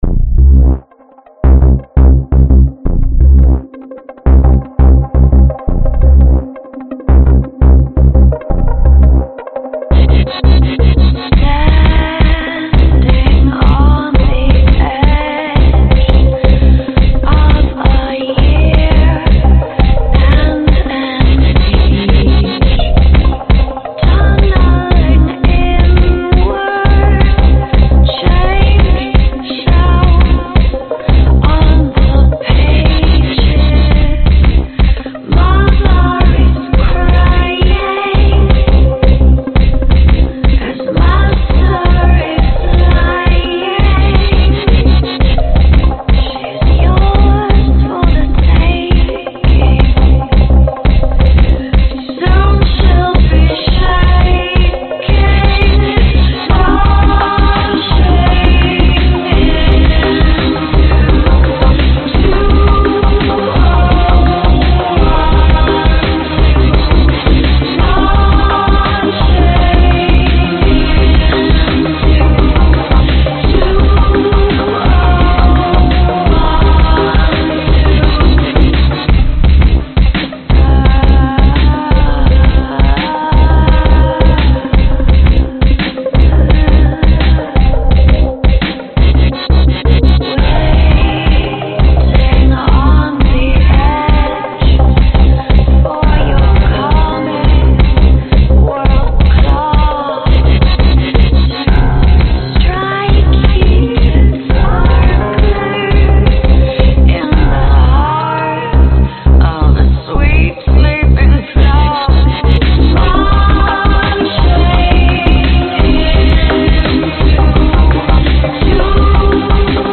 描述：孩子们大喊大叫的样本，听起来就像孩子。
标签： 孩子 R 大呼小叫
声道立体声